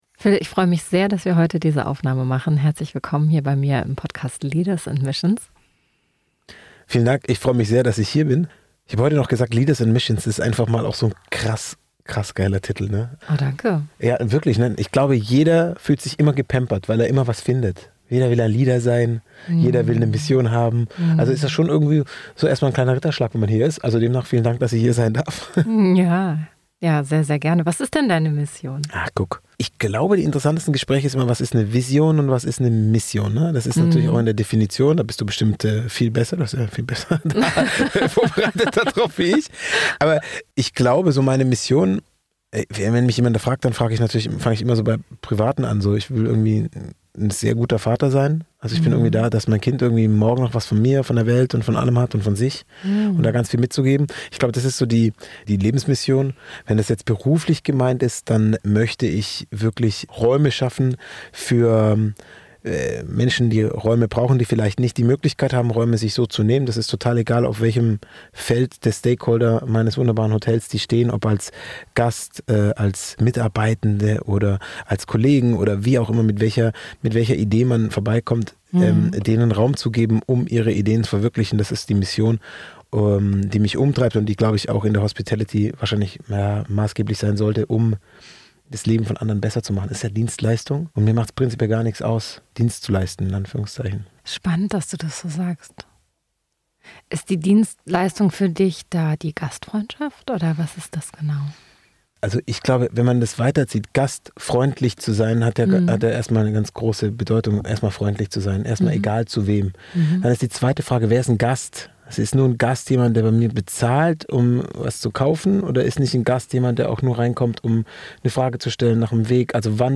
Ein Gespräch für alle, die Leadership nicht als Status, sondern als Verantwortung verstehen – sich selbst und anderen gegenüber.